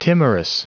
Prononciation du mot timorous en anglais (fichier audio)
Prononciation du mot : timorous